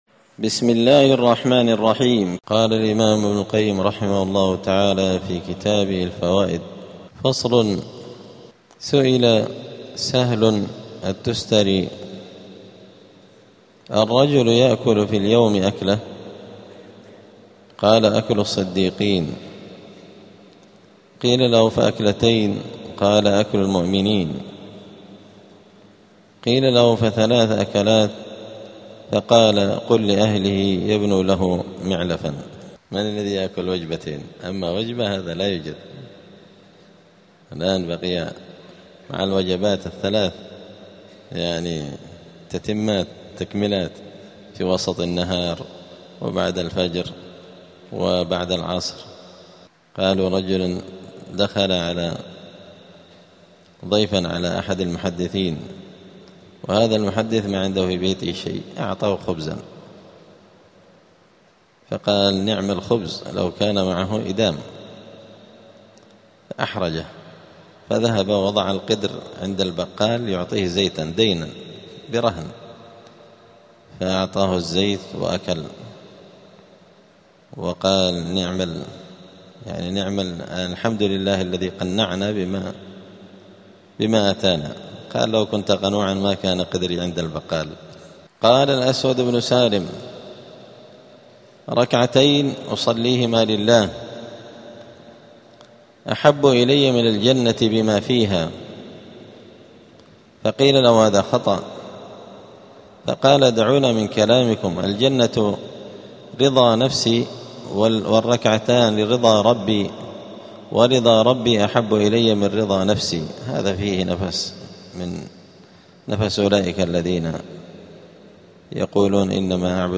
الأحد 27 ربيع الثاني 1447 هــــ | الدروس، دروس الآداب، كتاب الفوائد للإمام ابن القيم رحمه الله | شارك بتعليقك | 8 المشاهدات
دار الحديث السلفية بمسجد الفرقان قشن المهرة اليمن